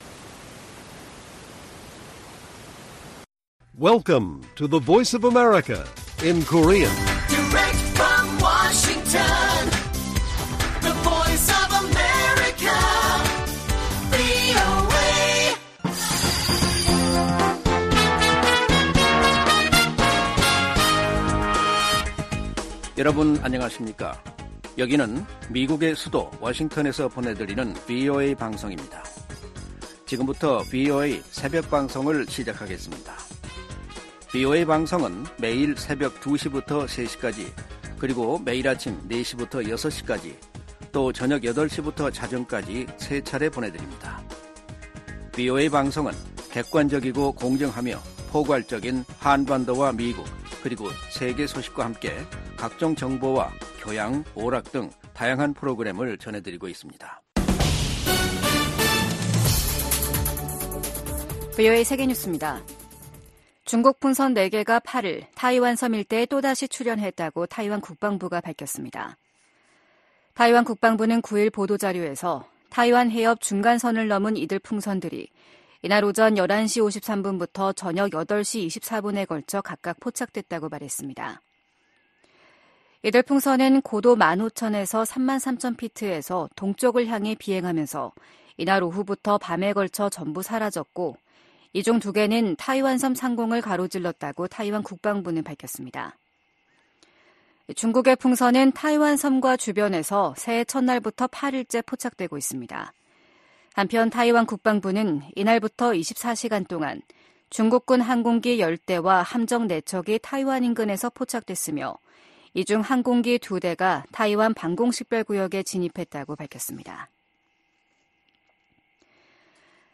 VOA 한국어 '출발 뉴스 쇼', 2024년 1월 10일 방송입니다. 미국이 북한에 도발 자제와 외교적 해결을 촉구하는 한편 한국에 확고한 방위 공약을 거듭 확인했습니다. 북한이 포격 도발을 한 것은 미한일 3국 협력 불만 표출과 총선을 앞둔 한국을 혼란시키려는 것으로 미 전문가들은 분석했습니다. 북한이 러시아에 첨단 단거리 미사일(SRBM)까지 넘긴 것으로 알려지면서 군사협력이 상당히 높은 수준에서 이뤄질 가능성이 제기되고 있습니다.